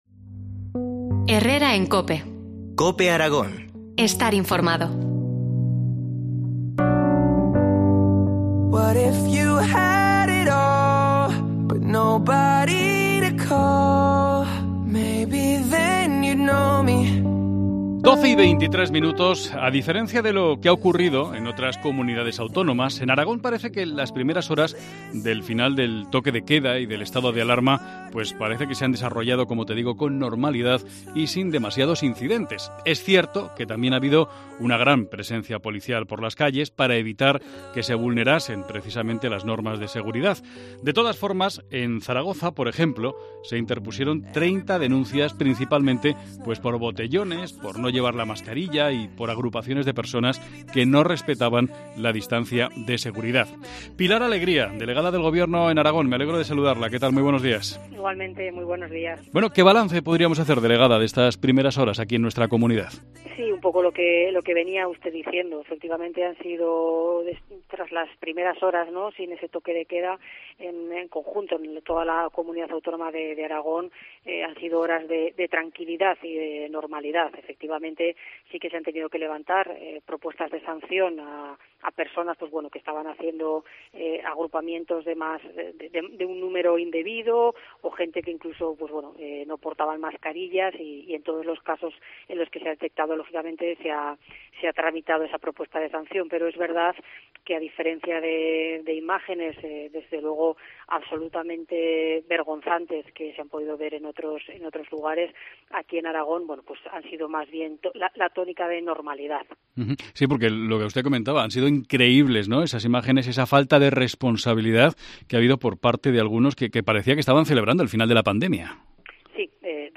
Entrevista a la Delegada del Gobierno en Aragón, Pilar Alegría.